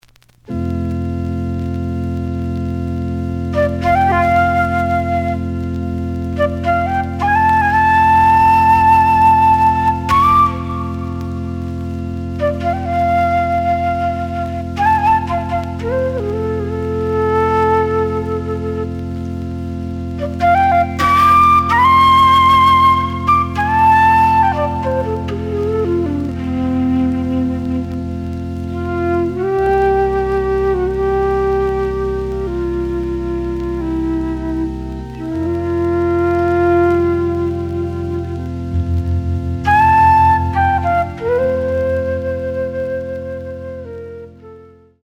The audio sample is recorded from the actual item.
●Genre: Jazz Funk / Soul Jazz
Slight edge warp.